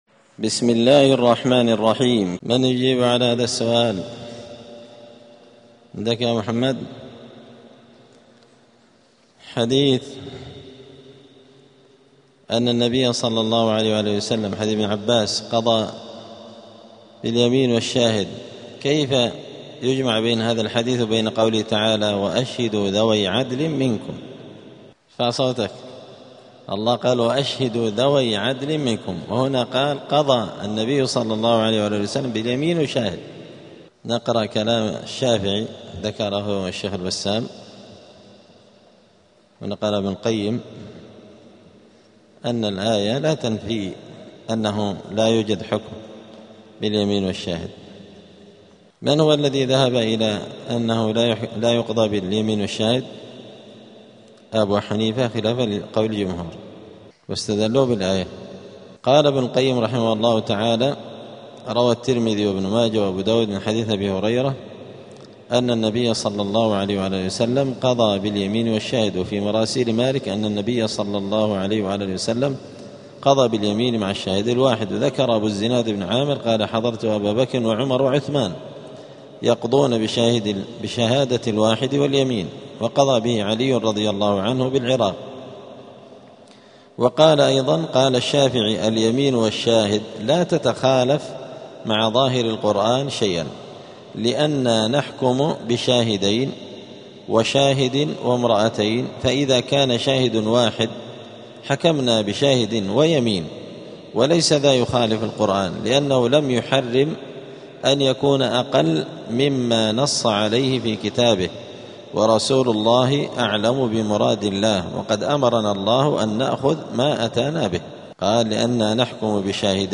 *الدرس الواحد والعشرون (21) {ﺑﺎﺏ اﻟﺪﻋﺎﻭﻯ ﻭاﻟﺒﻴﻨﺎﺕ}*
دار الحديث السلفية بمسجد الفرقان قشن المهرة اليمن